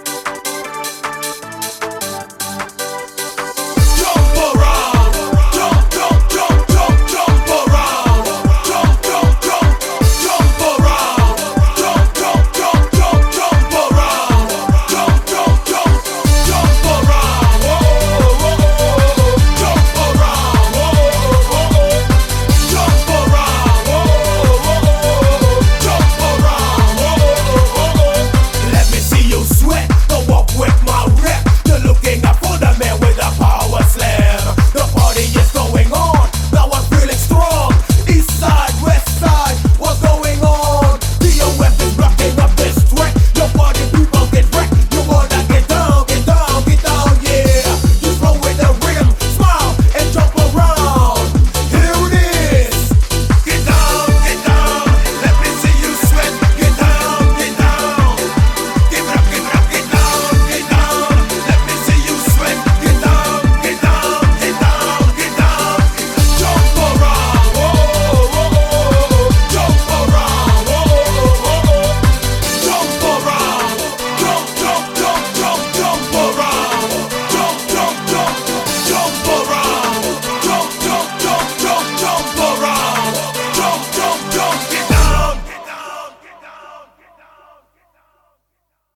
BPM153--1
Audio QualityMusic Cut
- Music from custom cut